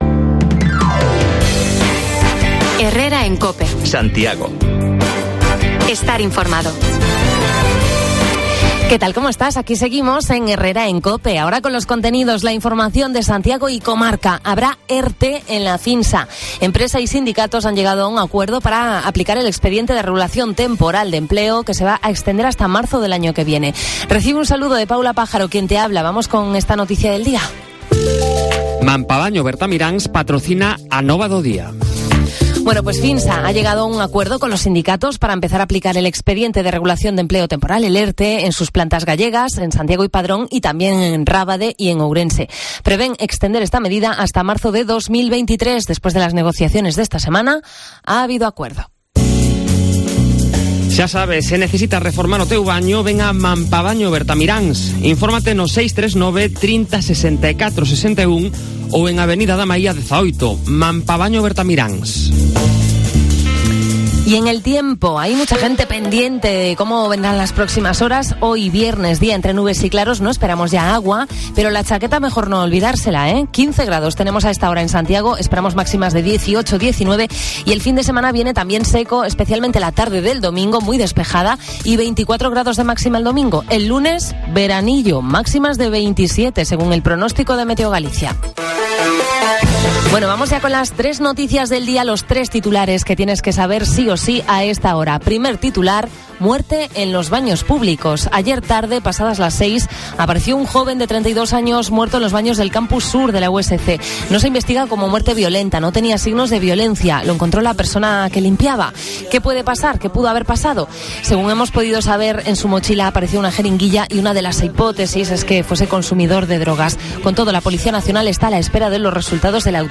Paseamos por el Ensanche para contarte cómo es la muestra fotográfica Compostela Photo, que cubre 15 garajes del centro con vinilos de fotógrafos consagrados y emergentes y con temática xacobea.